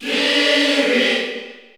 Category: Crowd cheers (SSBU) You cannot overwrite this file.
Kirby_Cheer_Spanish_PAL_SSBU.ogg